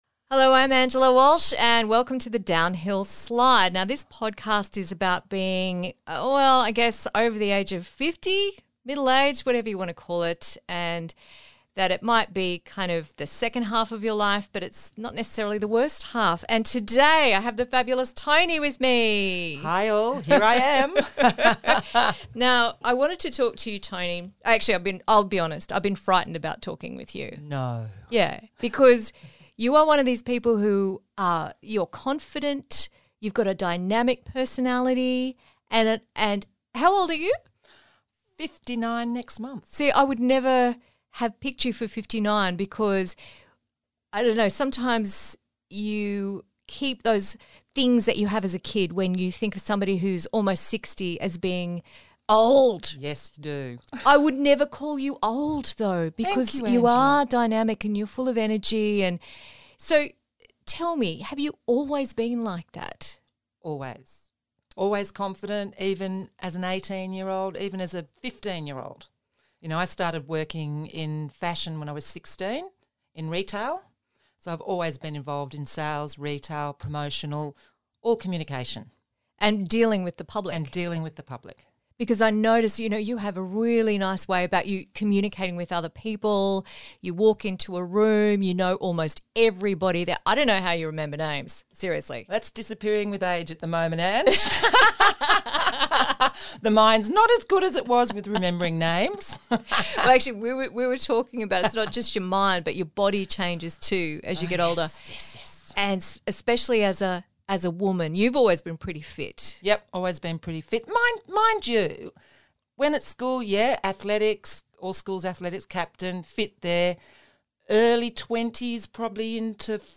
A chat about confidence, clothes, and making the most of yourself